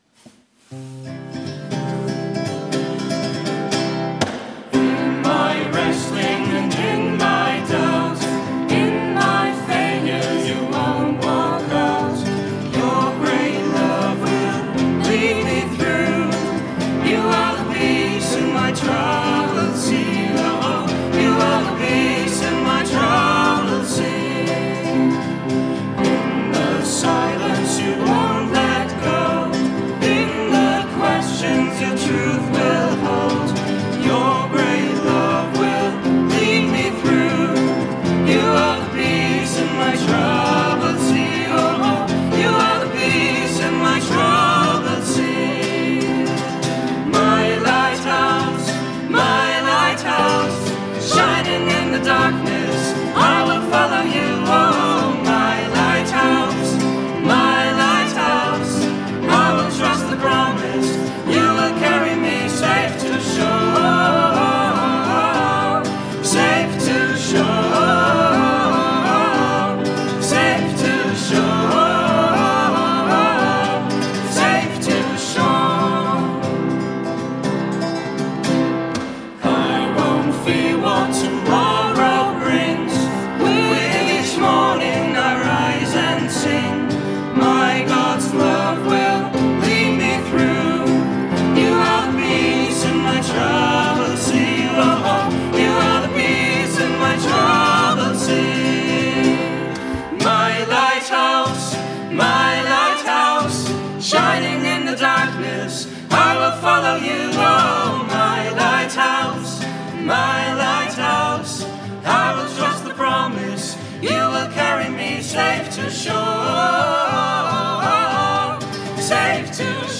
Es war ein seltsamer Ostergottesdienst, mit kleiner Besetzung in unserer Johanneskirche, weil gerade in diesen Tagen die Inzidenzzahlen so in die Höhe gegangen waren (schön, dass in der Zeitung so positiv darüber berichtet wurde, siehe Anhang).
Und dennoch haben wir gefeiert: laut und fröhlich, mit Posaunenchor und
kraftvollem Gesang, im Licht des Ostersonntags und der Osterkerze und mit der wunderbaren Botschaft, dass der Auferstandene gerade zu denen kommt, die auf der Flucht sind vor Frust und Überforderung, vor Zukunftsangst und Schwermut.